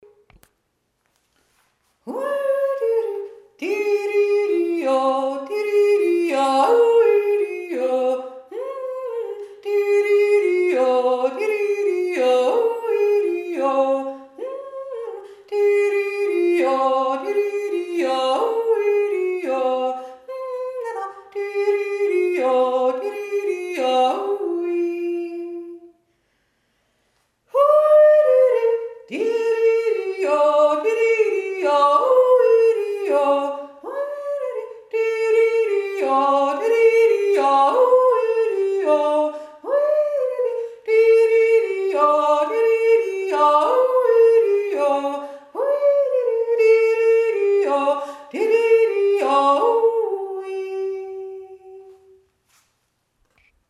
Goldegg jodelt März 2024
2. Stimme